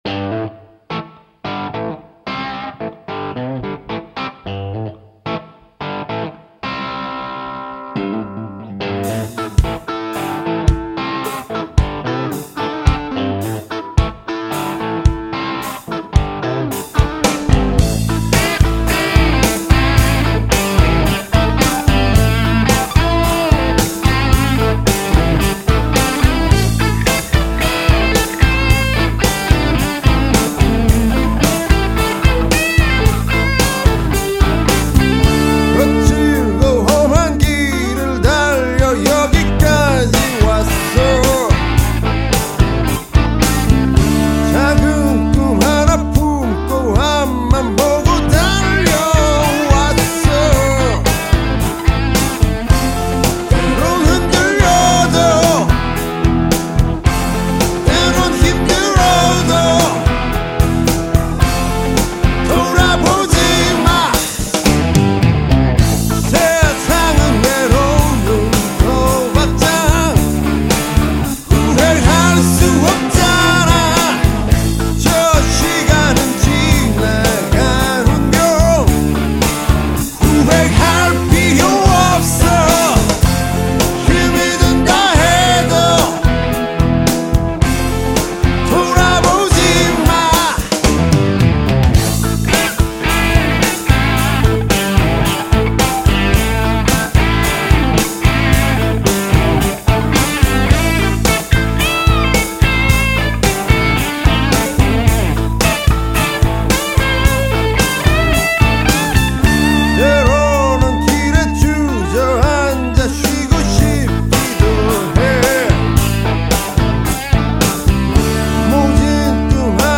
보컬, 기타
드럼